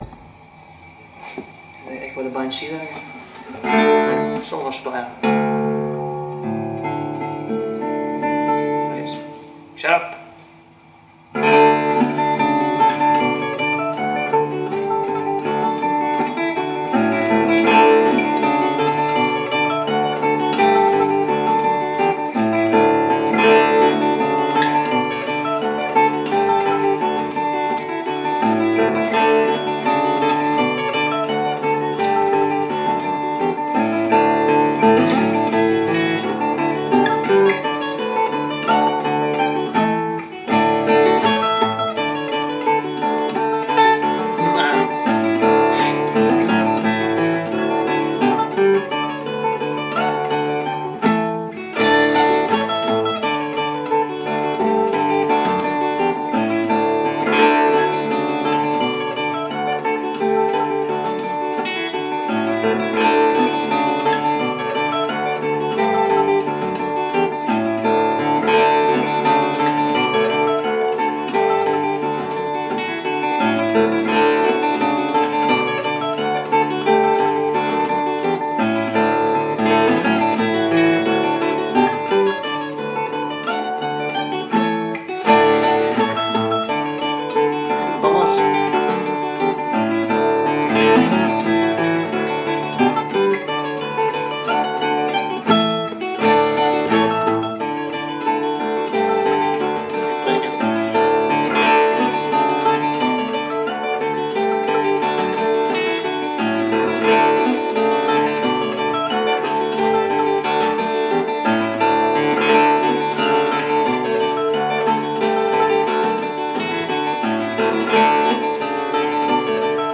playing fiddle